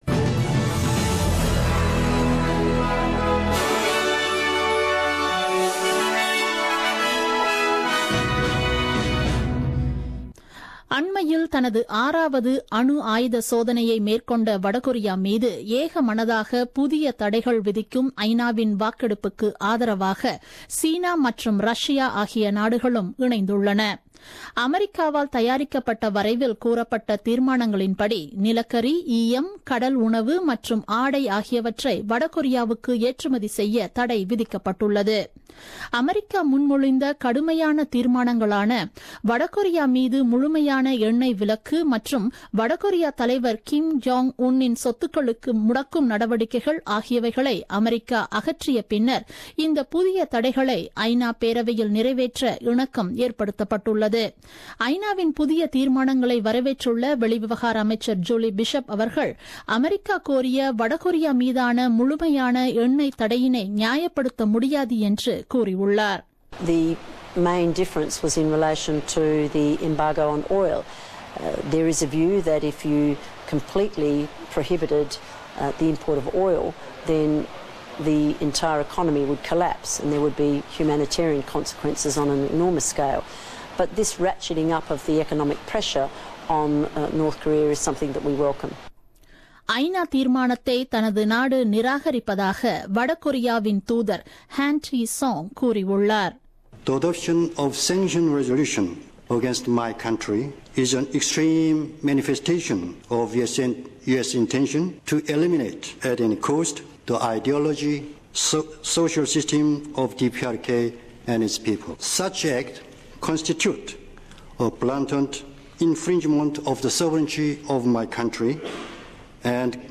The news bulletin broadcasted on 13th September 2017 at 8pm.